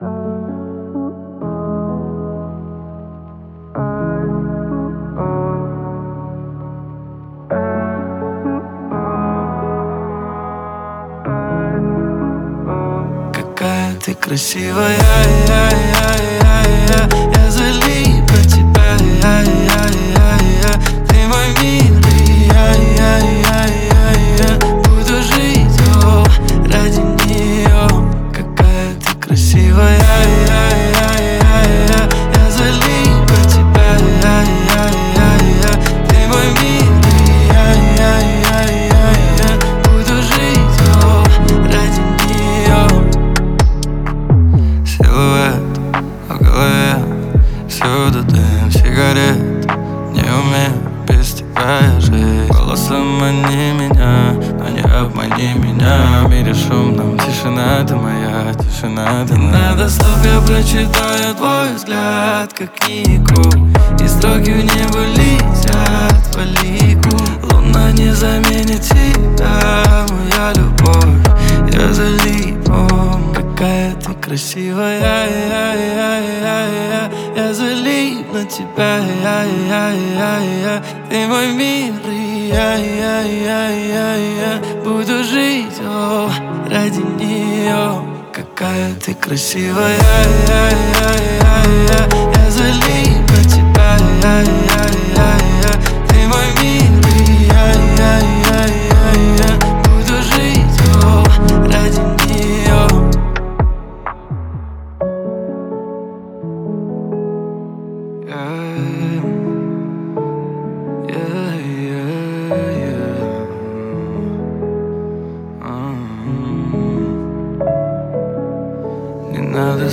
Категория Хип-хоп